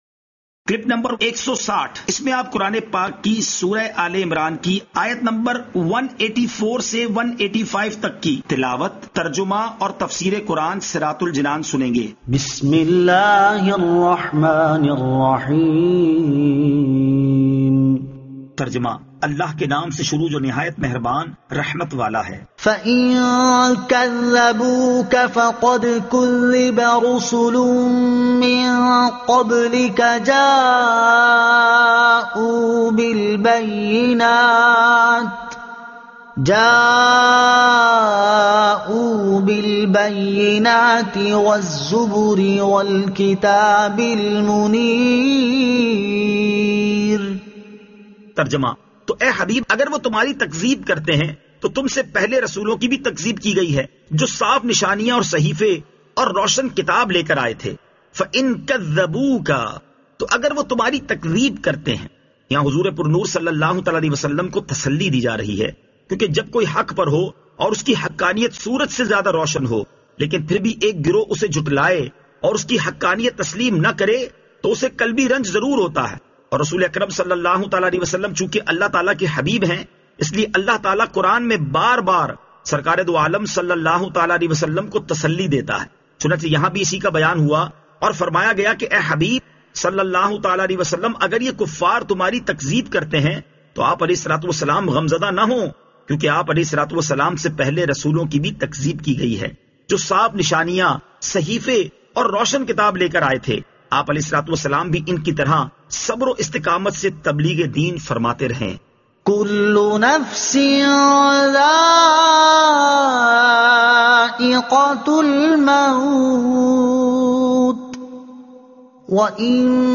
Surah Aal-e-Imran Ayat 184 To 185 Tilawat , Tarjuma , Tafseer